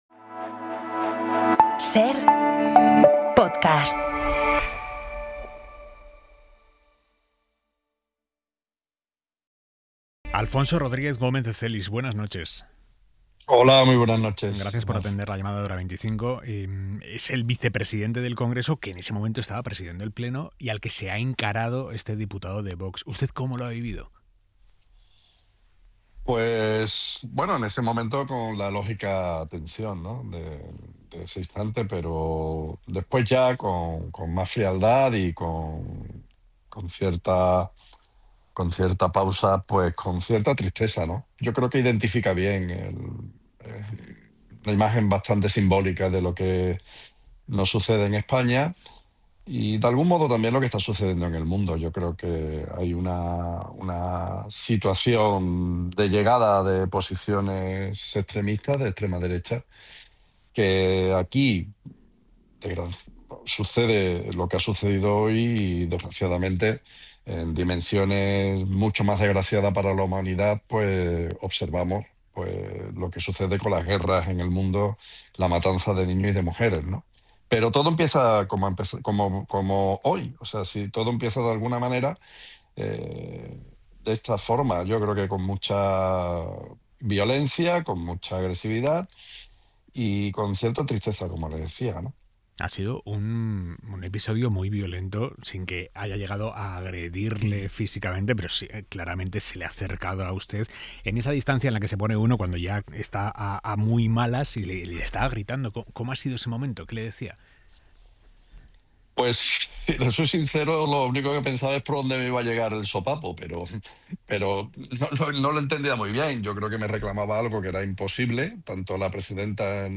Aimar Bretos entrevista a Alfonso R. Gómez de Celis, vicepresidente del Congreso